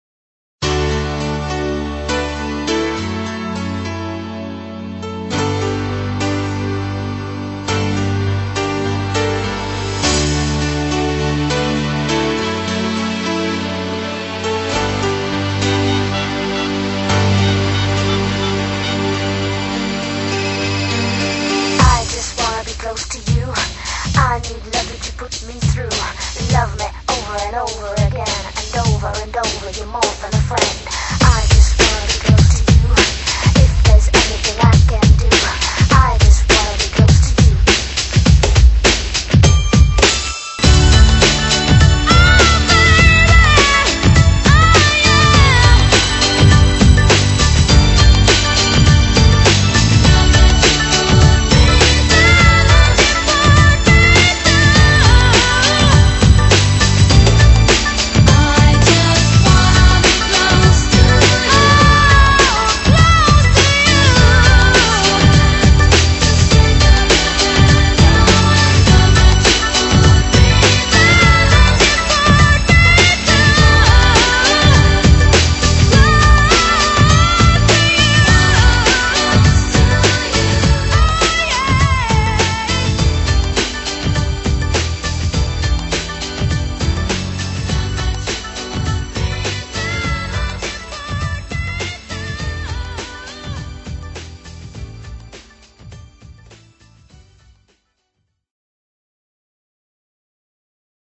BPM153--1
Audio QualityPerfect (High Quality)